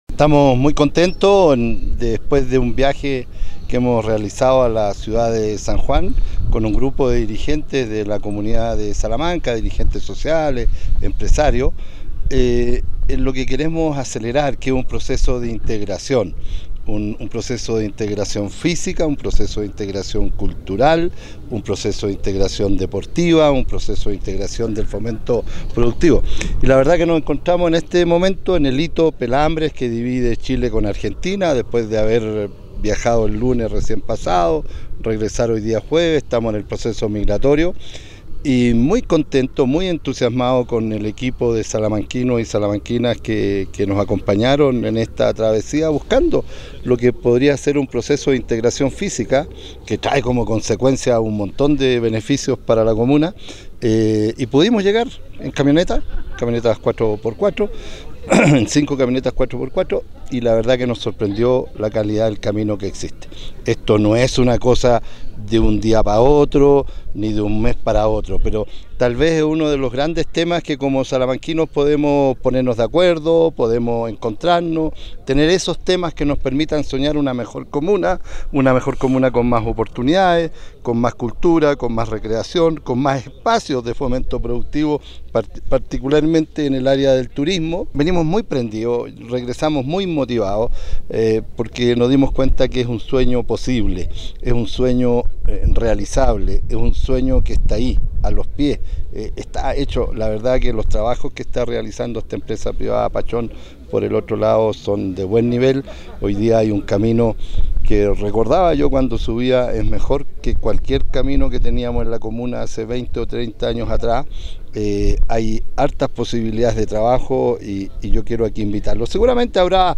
Finalmente, tras la serie de reuniones de gestión política y de negocios realizadas allende la cordillera, entre la delegación de salamanquinos y los dueños de casa,  el alcalde de la comuna de Salamanca, Gerardo Rojas Escudero,  concluyó: